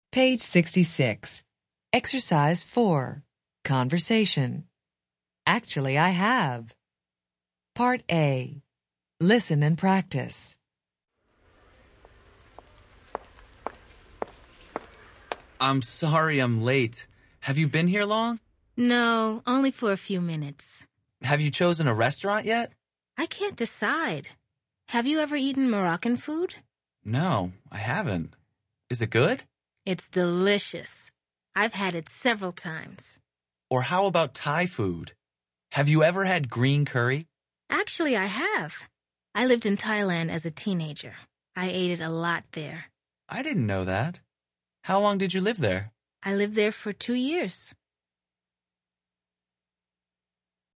Interchange Third Edition Level 1 Unit 10 Ex 4 Conversation Track 30 Students Book Student Arcade Self Study Audio